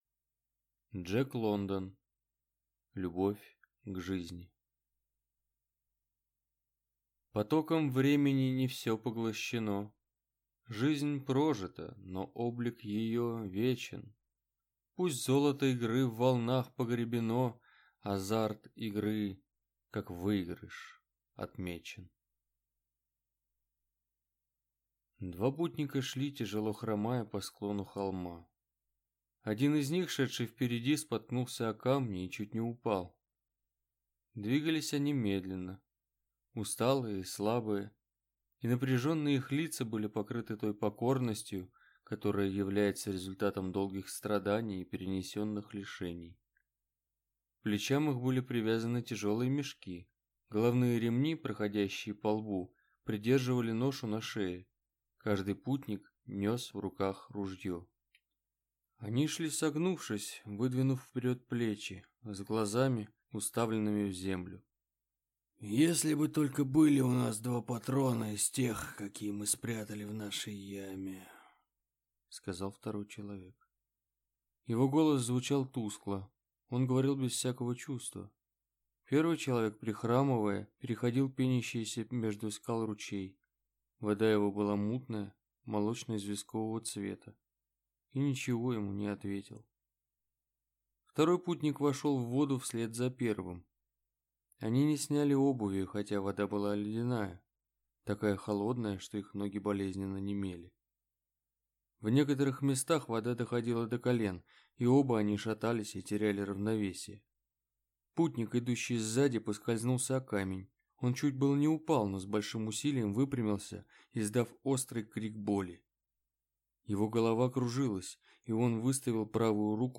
Аудиокнига Любовь к жизни | Библиотека аудиокниг